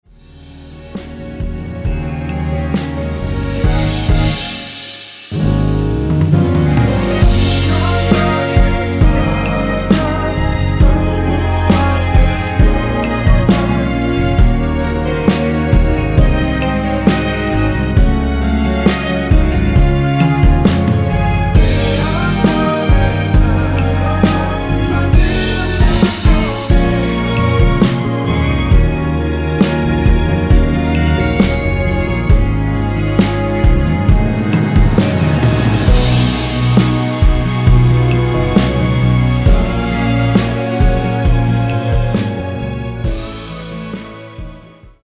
원키에서(-2)내린 코러스 포함된 MR입니다.
Db
앞부분30초, 뒷부분30초씩 편집해서 올려 드리고 있습니다.